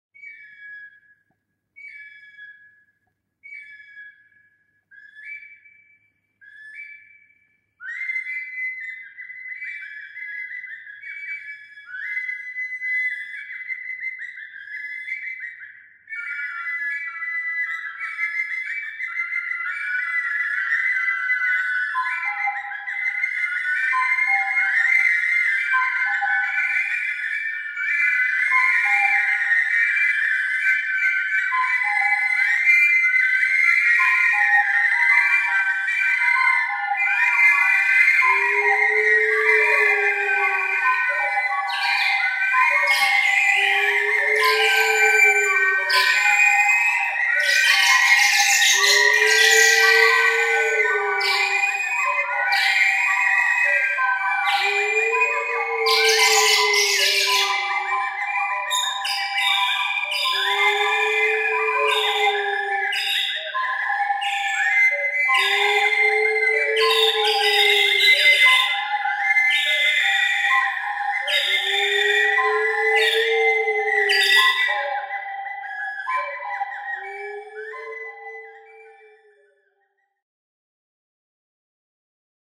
Gamtos garsai.mp3